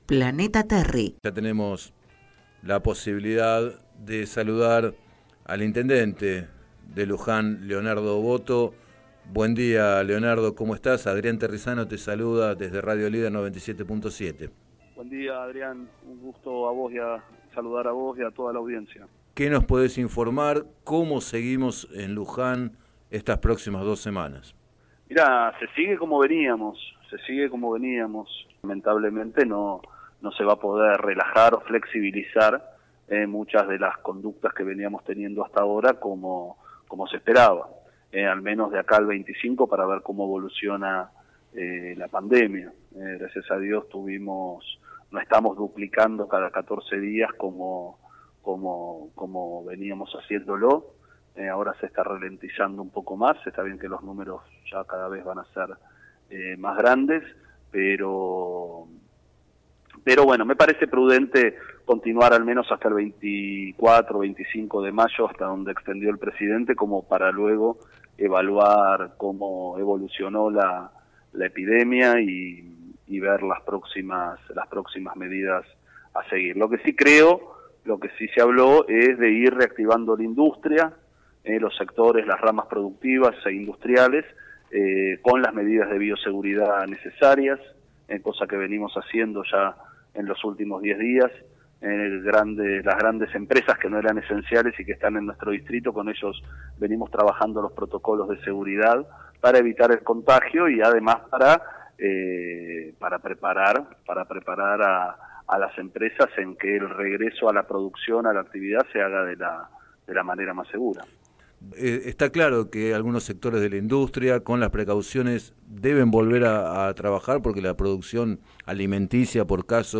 En declaraciones al programa Planeta Terri, Boto llamó a los lujanenses a no relajarse, sostener la cuarentena y las medidas de prevención sanitaria.